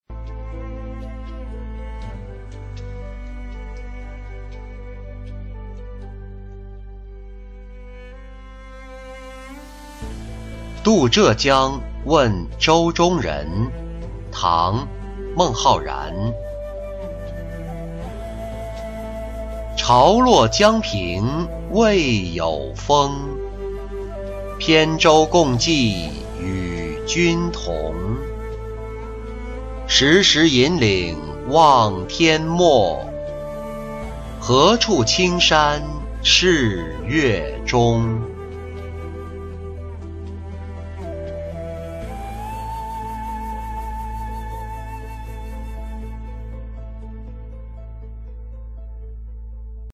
渡浙江问舟中人-音频朗读